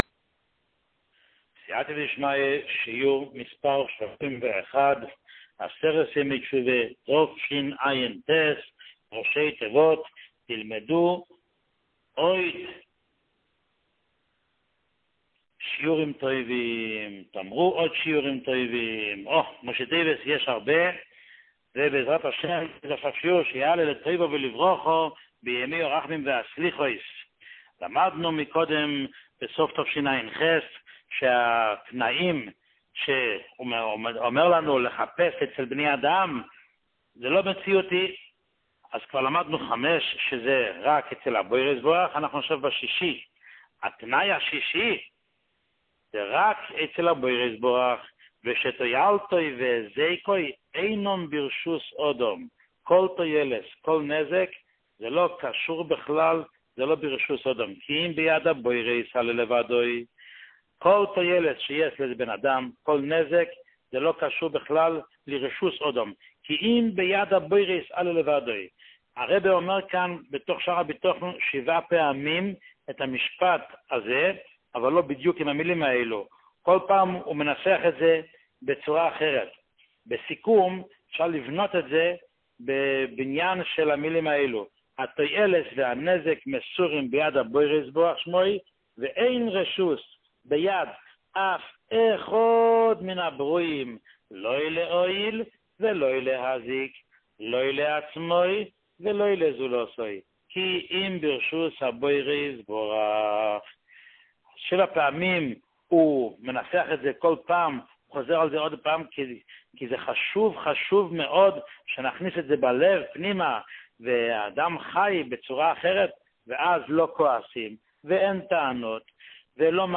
שיעור 31